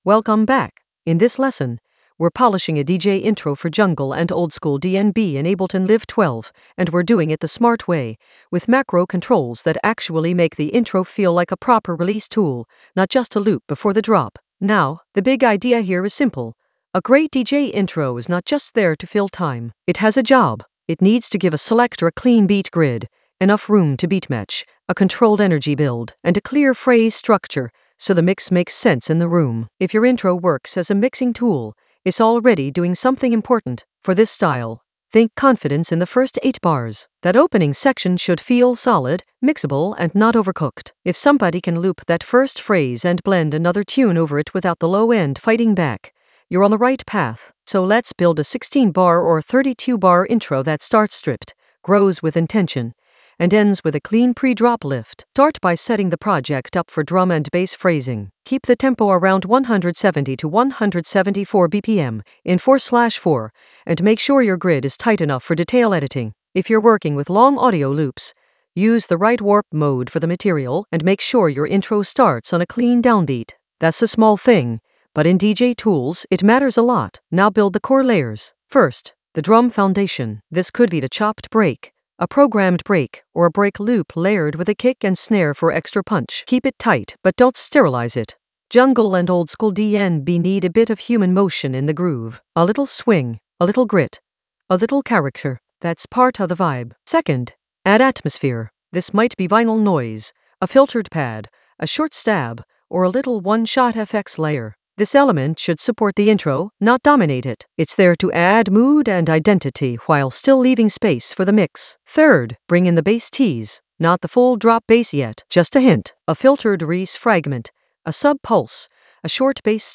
Narrated lesson audio
The voice track includes the tutorial plus extra teacher commentary.